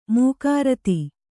♪ mūkārati